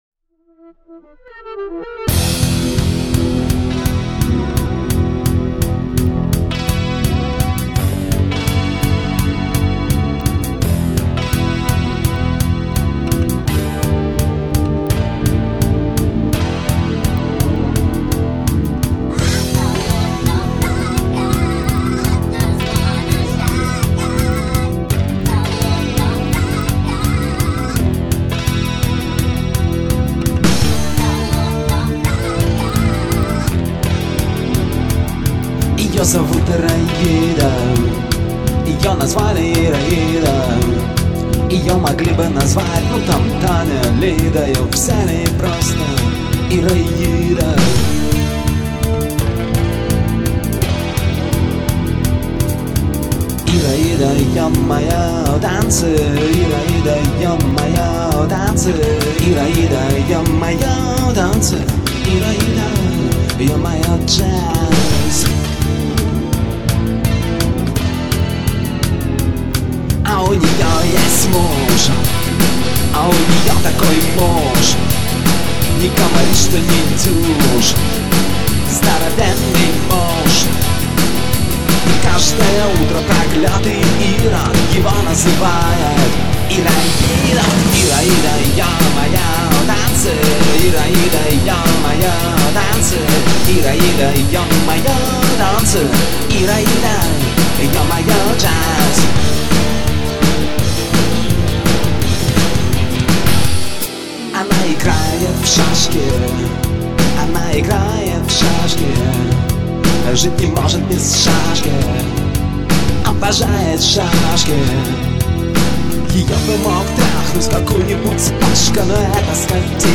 Альтернативная (2891)